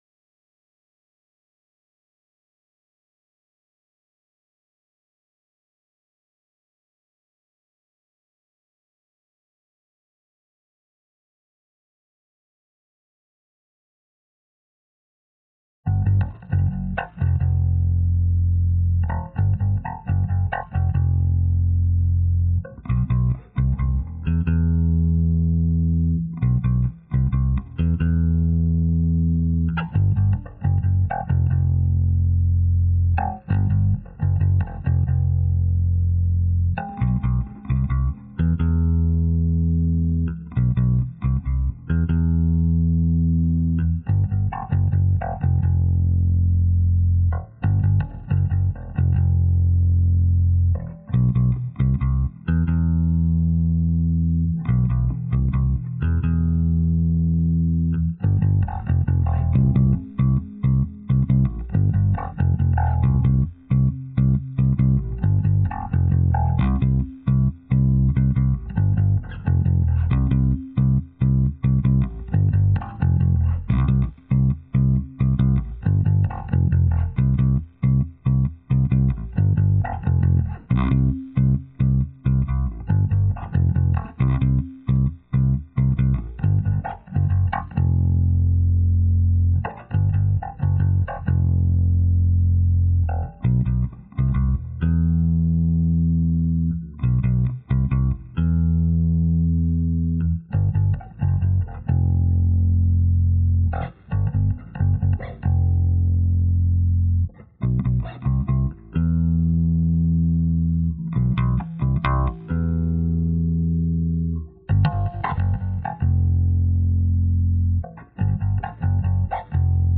Faith - Reaper Remix_freeze_Faith_Bass High-001.wav